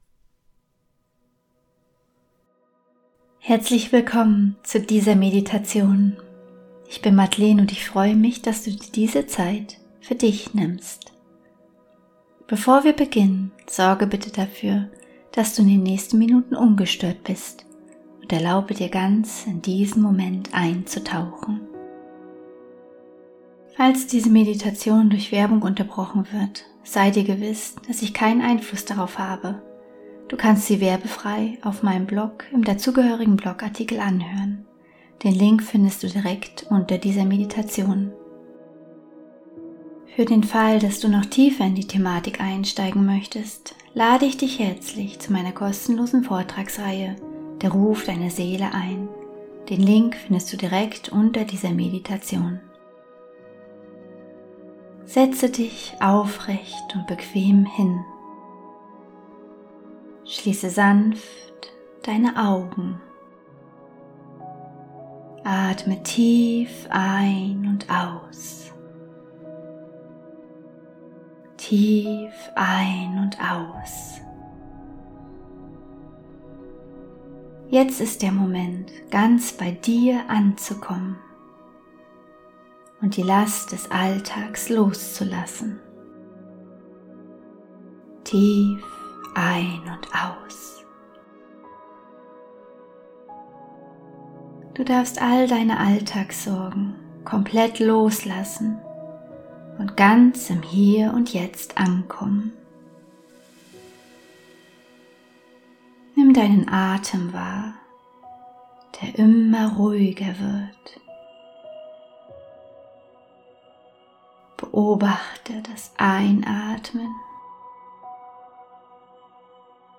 12 Minuten geführte Meditation: Von Kontrolle zu tiefem Urvertrauen ~ Heimwärts - Meditationen vom Funktionieren zum Leben Podcast
Meditation_Urvertrauen_im_Fluss_des_Lebens.mp3